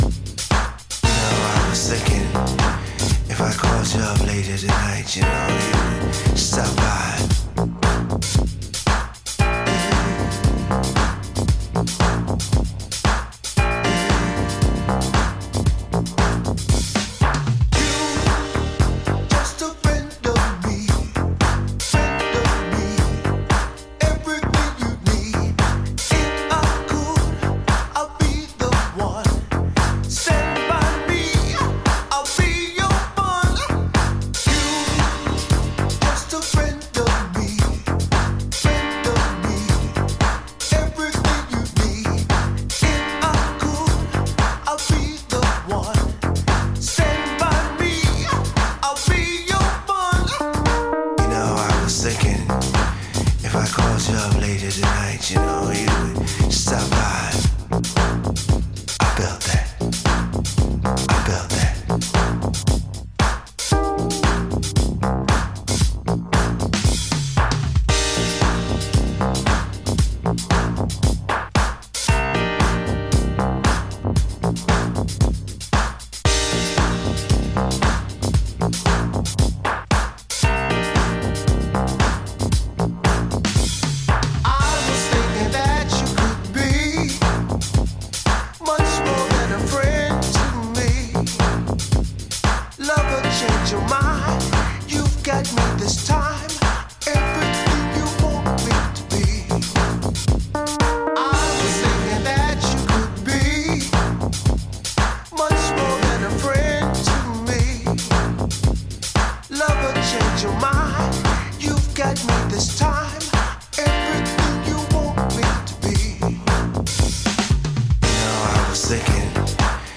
ジャンル(スタイル) NU DISCO / DEEP HOUSE